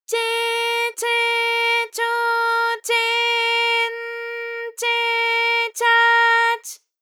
ALYS-DB-001-JPN - First Japanese UTAU vocal library of ALYS.
che_che_cho_che_n_che_cha_ch.wav